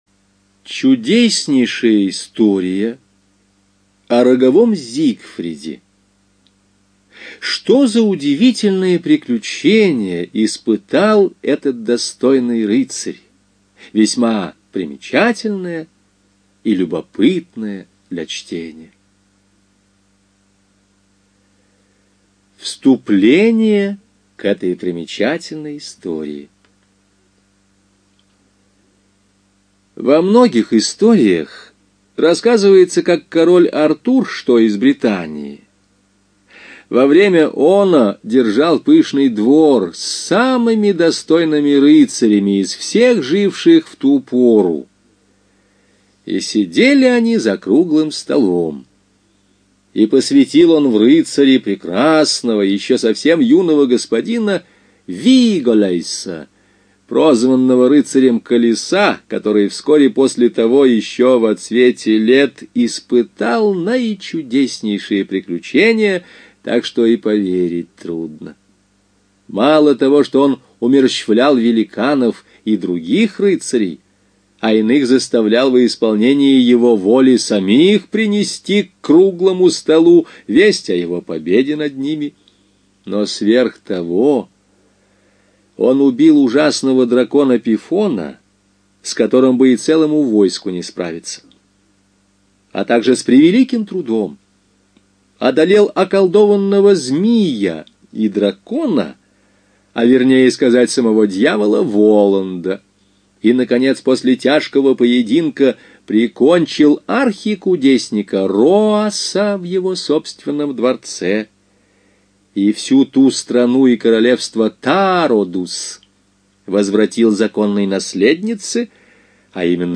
ЖанрЭпос
Студия звукозаписиЛогосвос